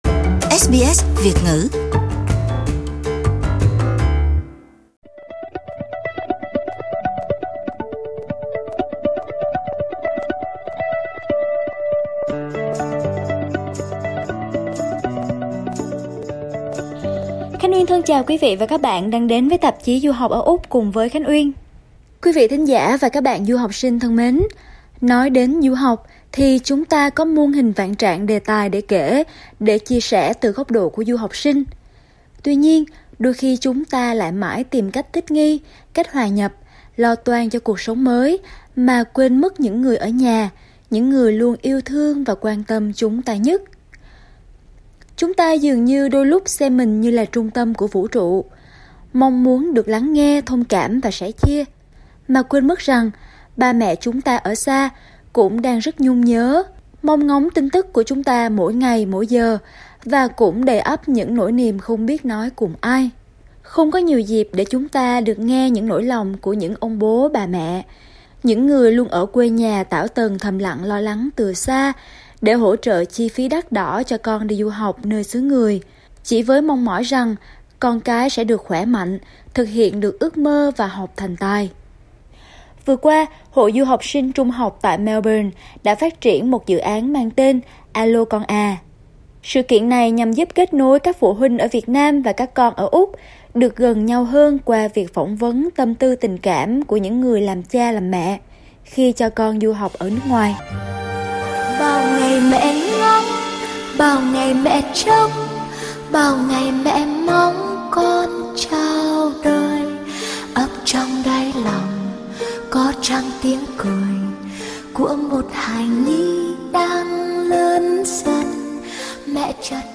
Nghe những chia sẻ của các bậc cha mẹ có con đi du học ở Úc.